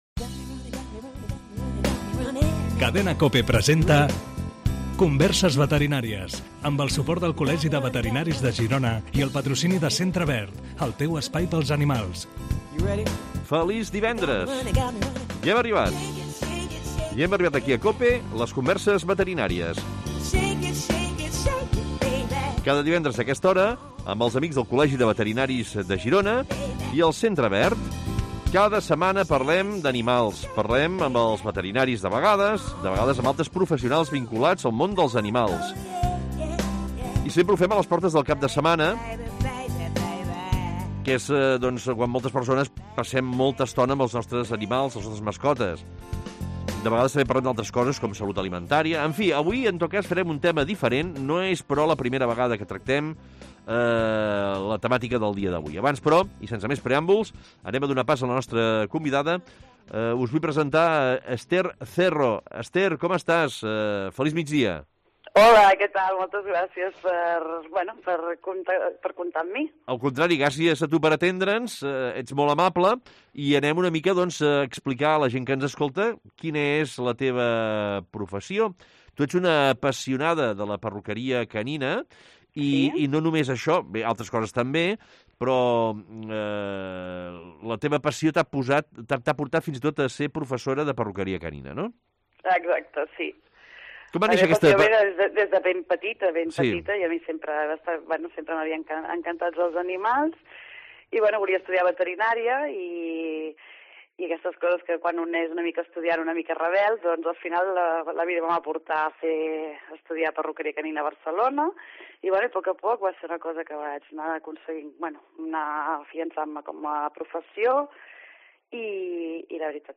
Es contesta per ràdio a les preguntes de propietaris de gossos i gats.